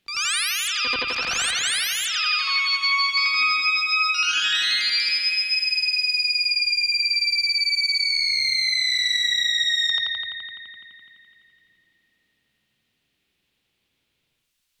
Malfunction.wav